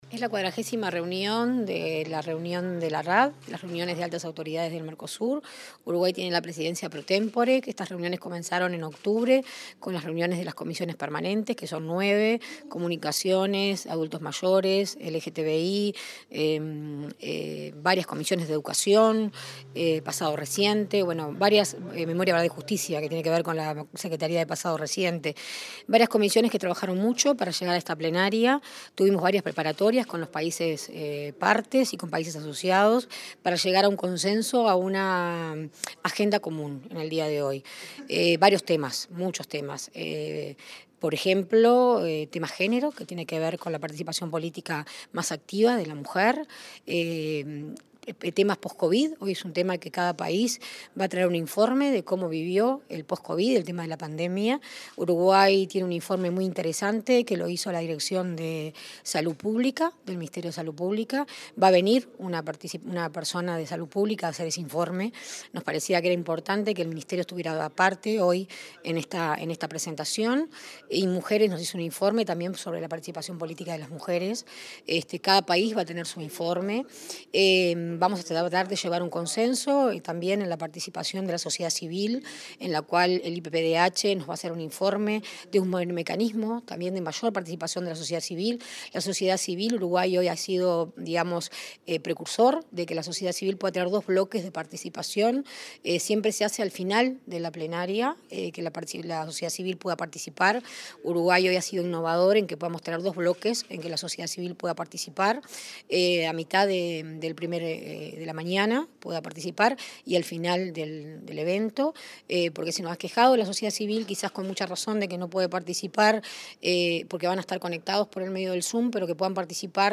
Entrevista a la secretaria de Derechos Humanos de Presidencia
Entrevista a la secretaria de Derechos Humanos de Presidencia 10/11/2022 Compartir Facebook X Copiar enlace WhatsApp LinkedIn La secretaria de Derechos Humanos de Presidencia, Sandra Etcheverry, participó de la XL reunión de altas autoridades sobre derechos humanos del Mercosur, que se realizó este jueves 10 en forma virtual. Antes, dialogó con Comunicación Presidencial.